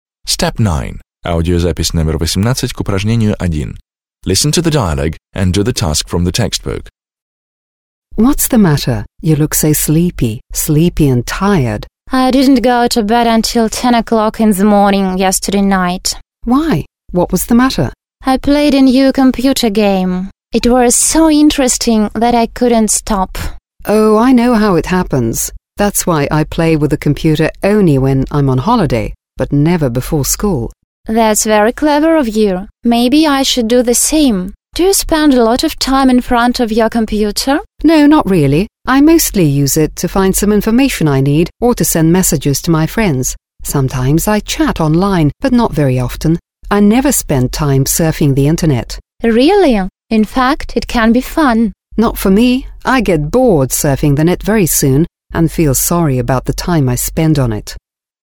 1. Listen to two friends talking, (18).
− Послушайте разговор двух друзей.